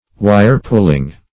Search Result for " wire-pulling" : The Collaborative International Dictionary of English v.0.48: Wire-pulling \Wire"-pull`ing\, n. The act of pulling the wires, as of a puppet; hence, secret influence or management, especially in politics; intrigue.